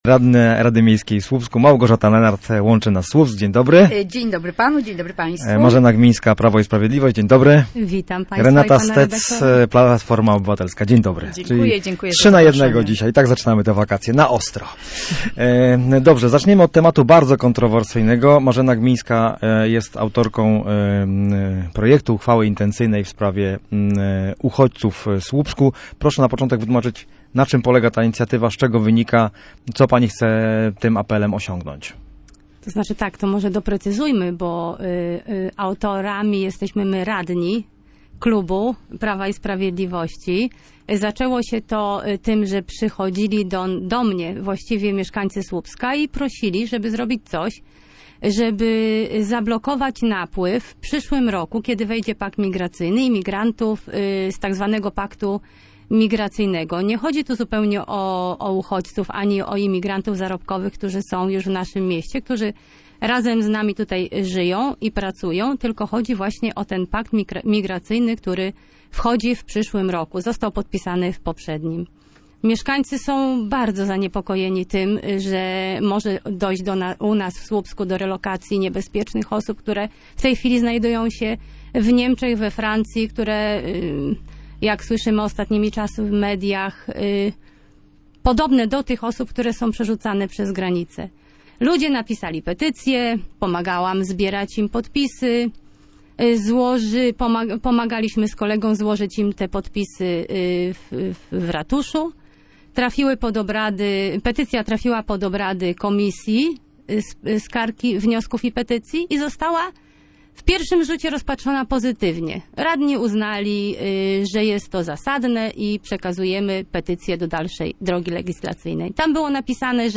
Sprawę w słupskim studiu Radia Gdańsk komentowały radne.